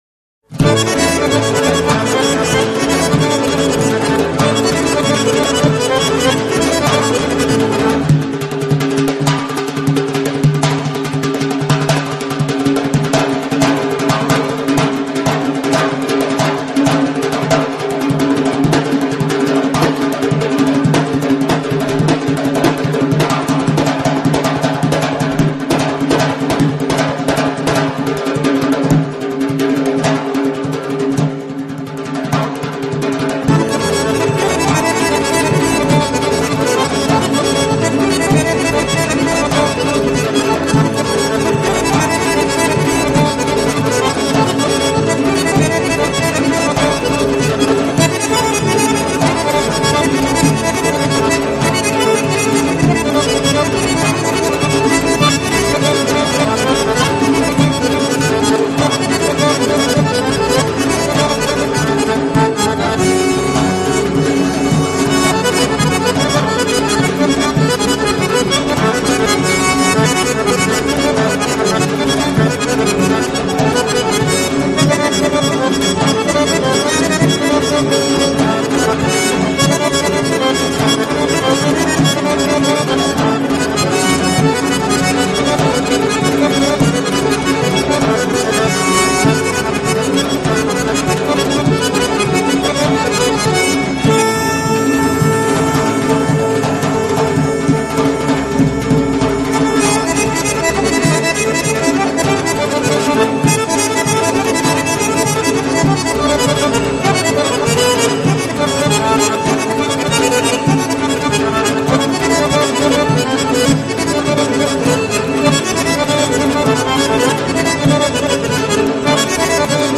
موسیقی آذری
قطعه موسیقی زیبای آذری
آهنگ آذری